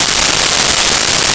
Nuclear Scanner Loop
Nuclear Scanner Loop.wav